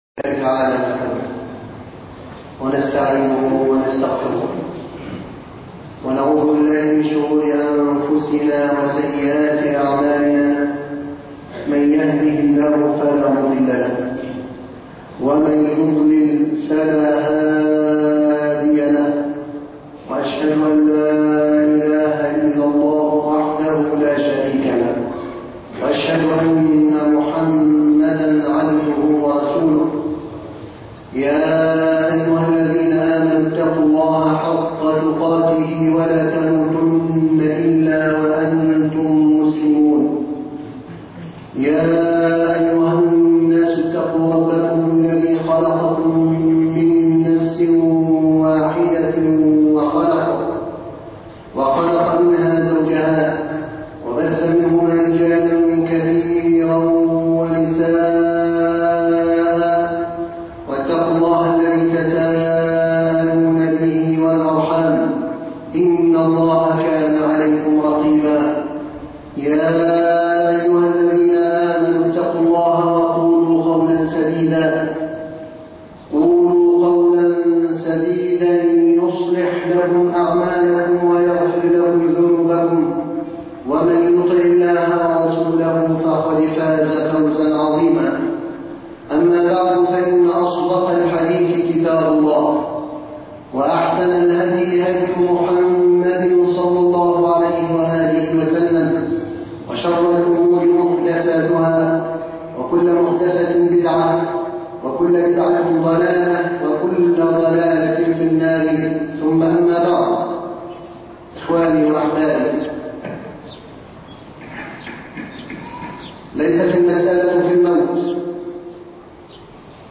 الشعارات وحدها لا تكفي ( خطب الجمعة